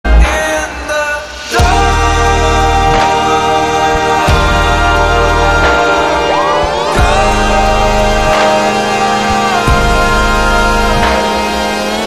• Качество: 112, Stereo
грустные
спокойные
soul
ballads
печальные